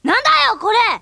Listen to Megumi as Ranma Saotome